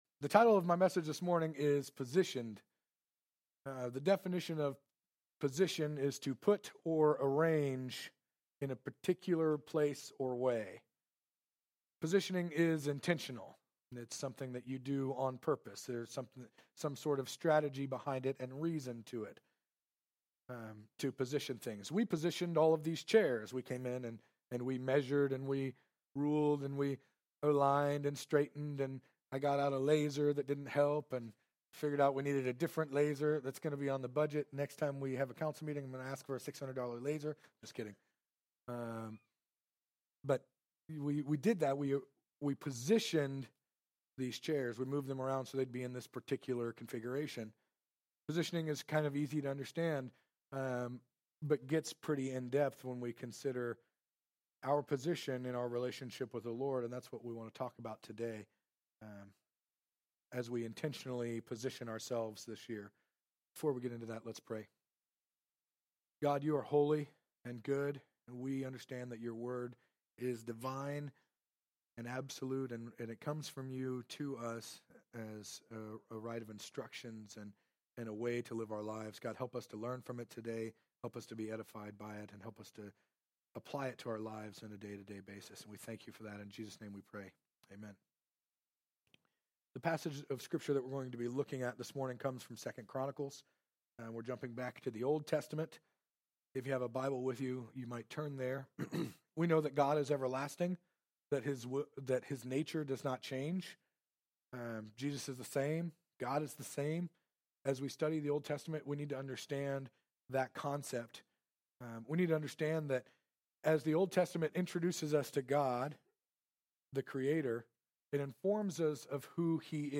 Sermons | Calvary Foursquare Church